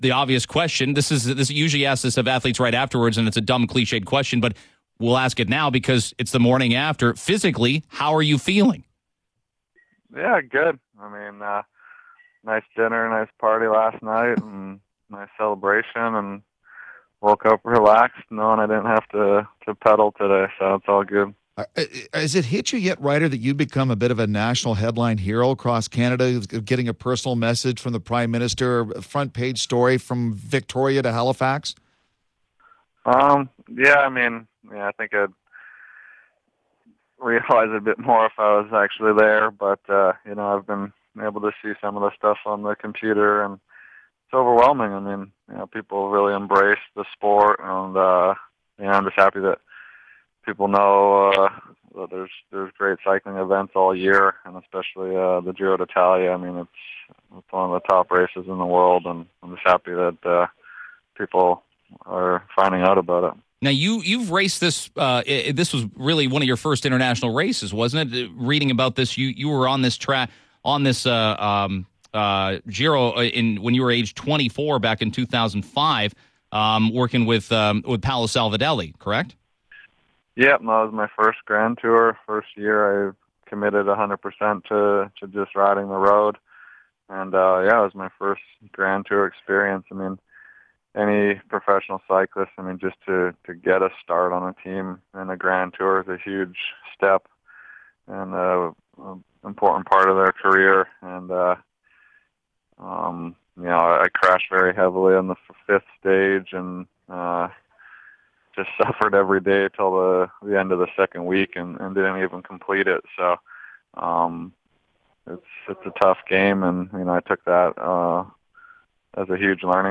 Listen to Ryder Hesjedal